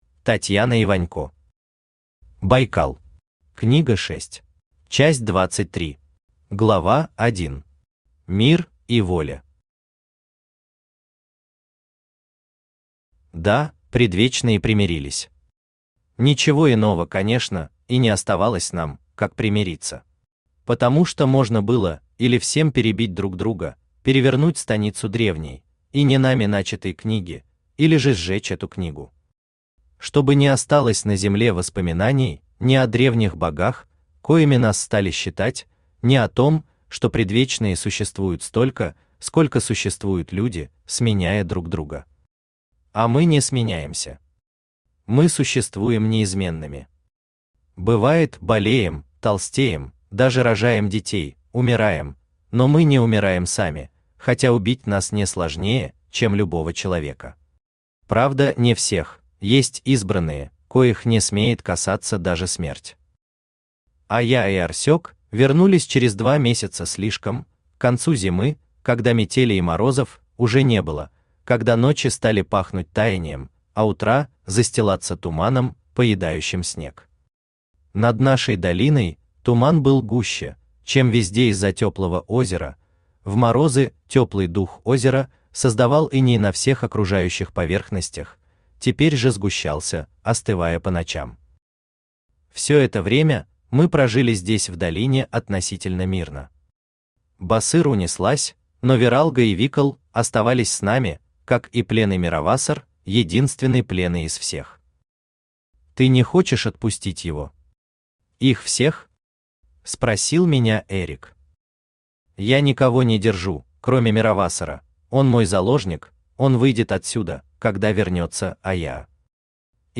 Аудиокнига Байкал. Книга 6 | Библиотека аудиокниг
Книга 6 Автор Татьяна Вячеславовна Иванько Читает аудиокнигу Авточтец ЛитРес.